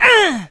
leon_hurt_vo_02.ogg